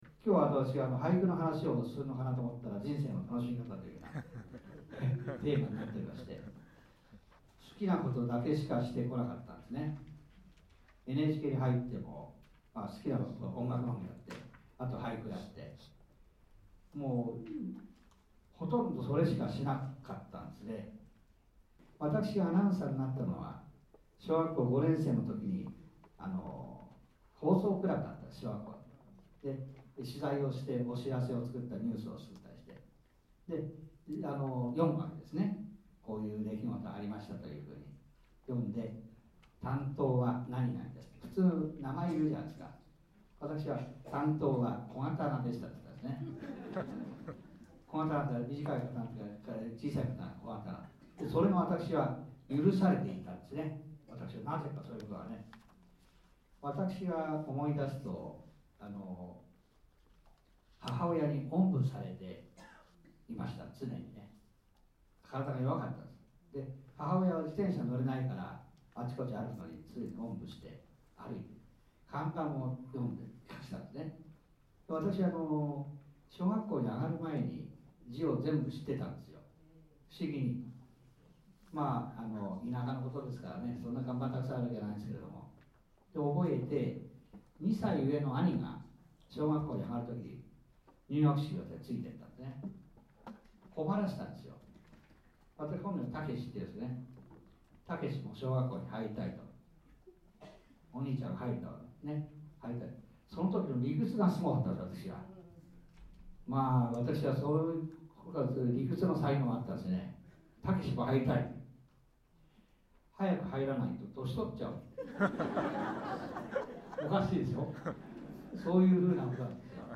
文化講演「人生の楽しみ方」の録音データ（約21分）